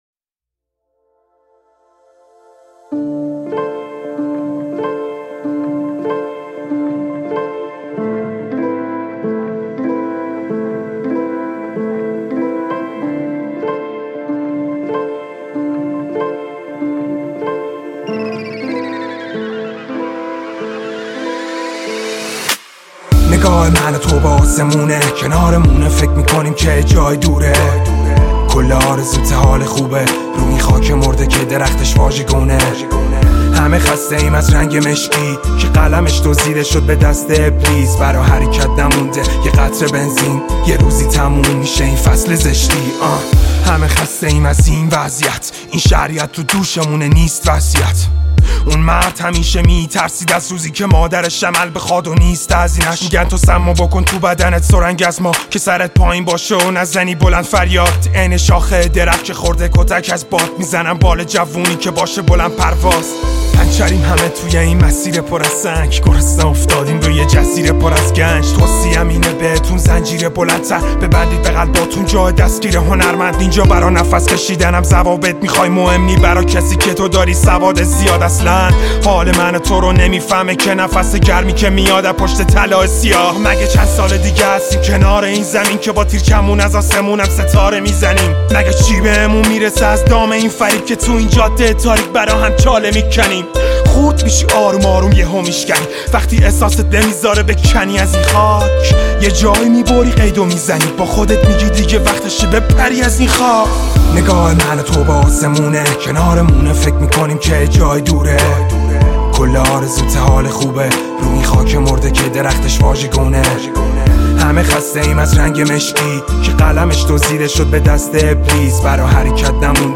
گنگ رپ